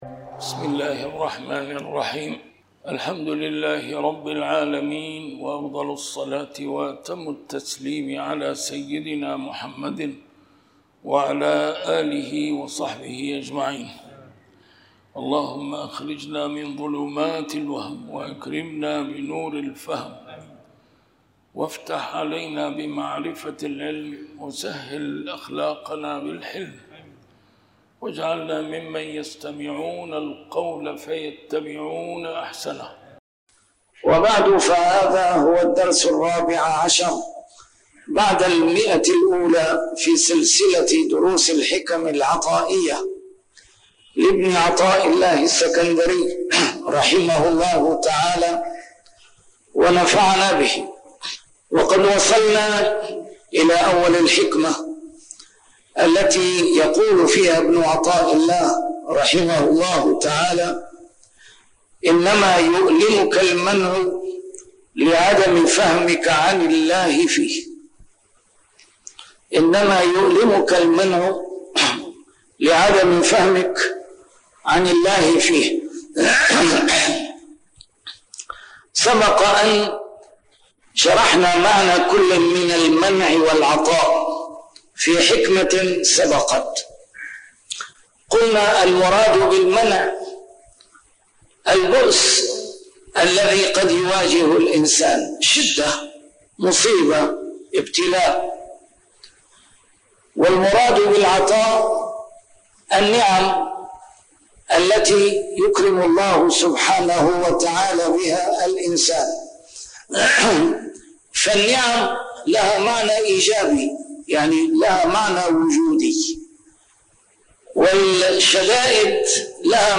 A MARTYR SCHOLAR: IMAM MUHAMMAD SAEED RAMADAN AL-BOUTI - الدروس العلمية - شرح الحكم العطائية - الدرس رقم 114 شرح الحكمة 94